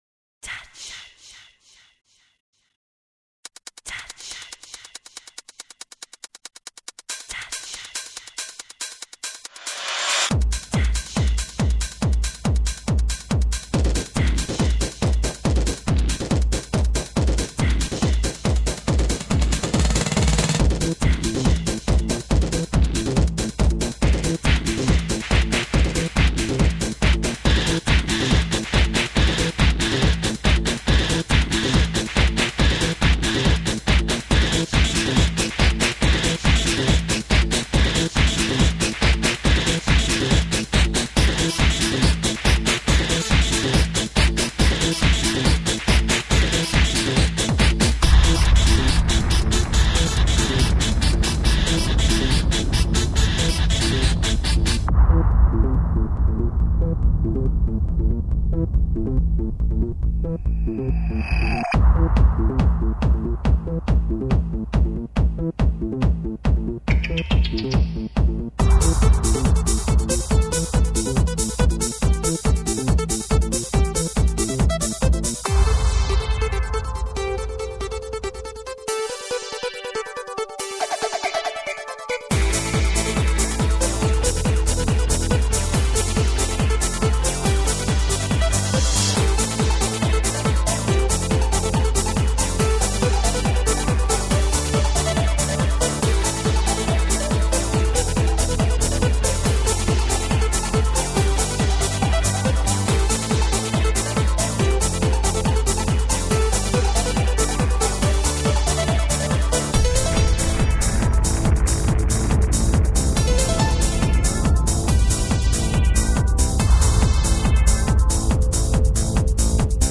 3,7 MB 1998 Trance